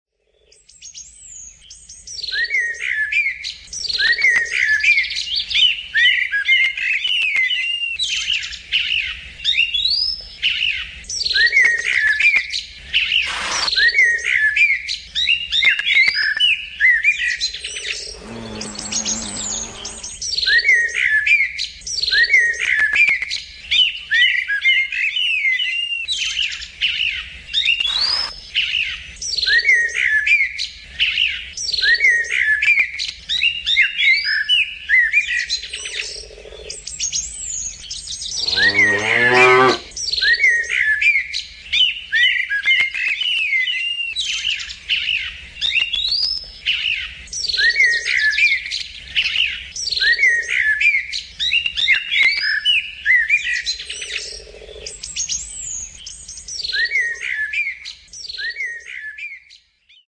Country Meadow
Category: Animals/Nature   Right: Personal